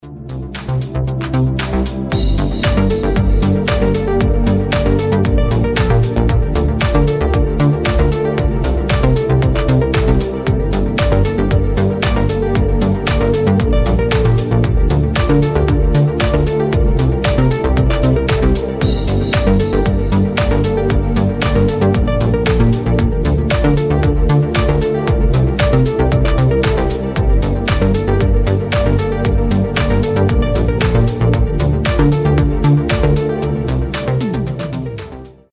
• Качество: 320, Stereo
громкие
без слов
Стиль: deep house